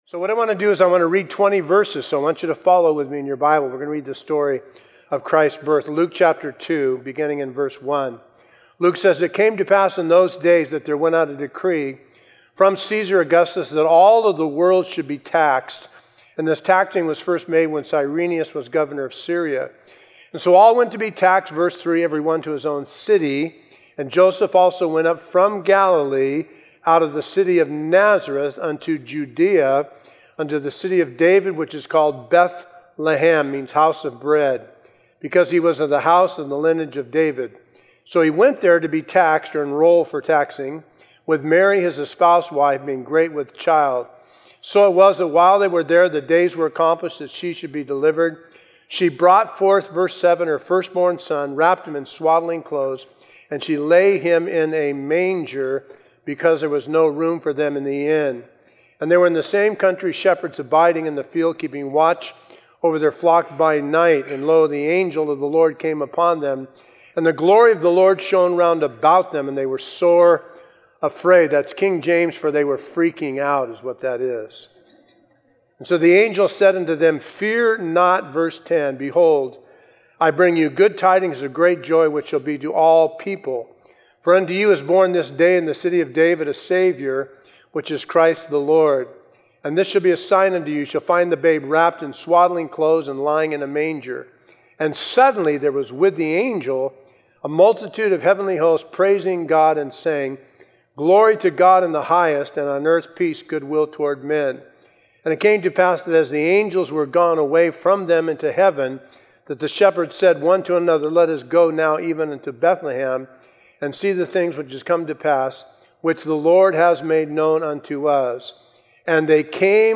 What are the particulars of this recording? a Christmas Eve message through Luke 2